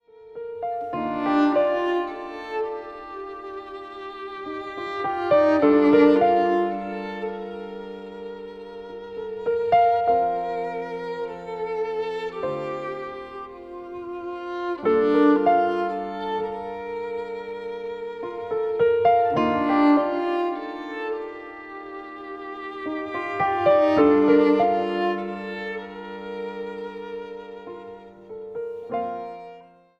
Zang | Solozang